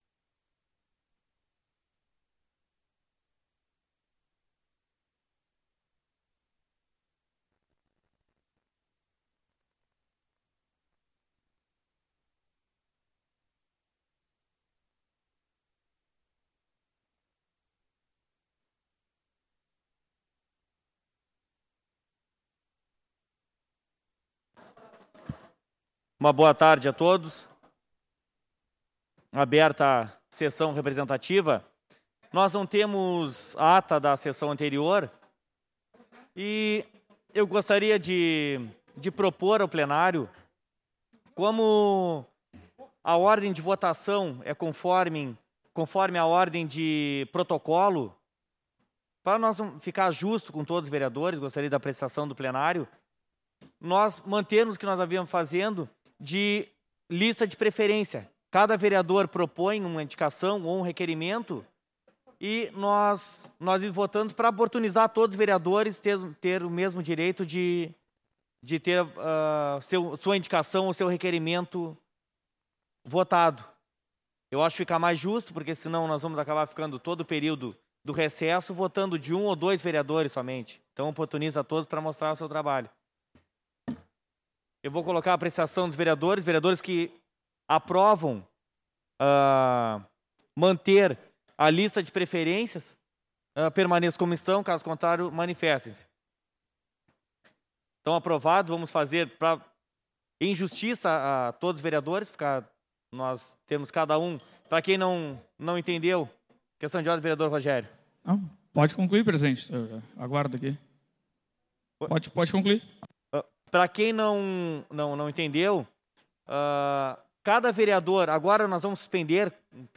Sessão Representativa 04/01/2021